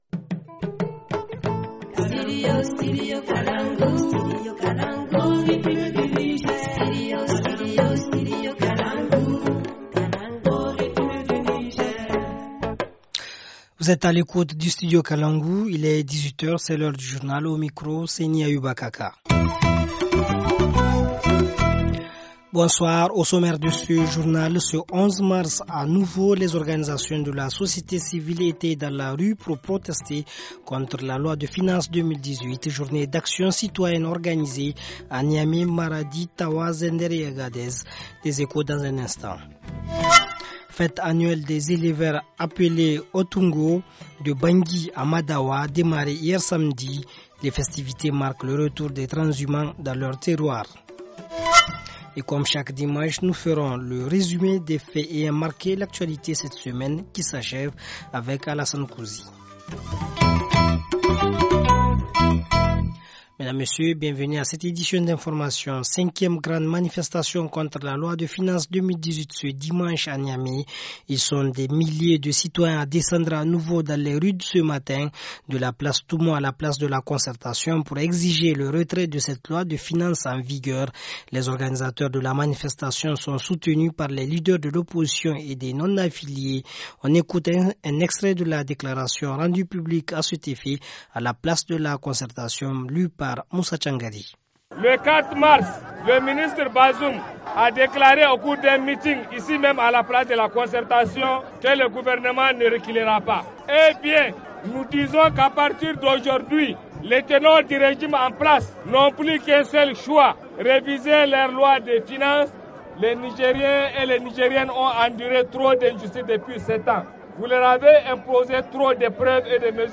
Journal du 11 mars 2018 - Studio Kalangou - Au rythme du Niger